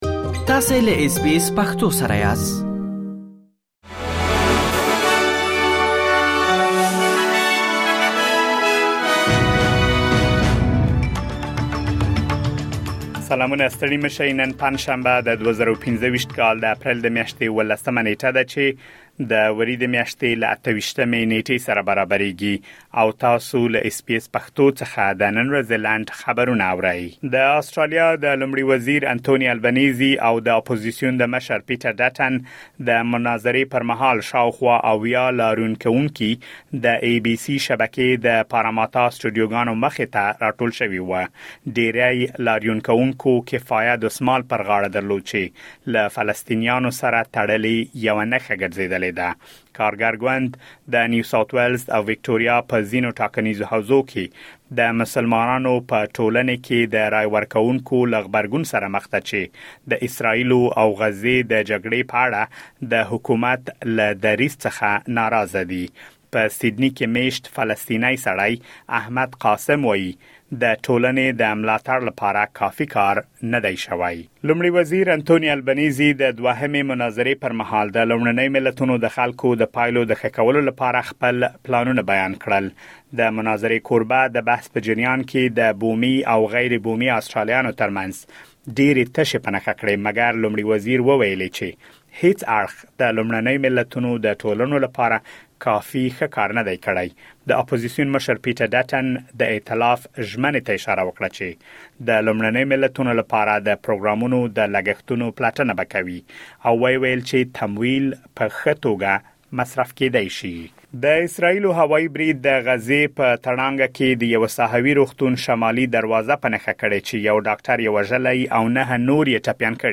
د اس بي اس پښتو د نن ورځې لنډ خبرونه | ۱۷ اپریل ۲۰۲۵